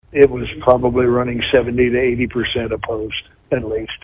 In an interview with KSOM/KS95 News, Moore says he has never favored the Bill.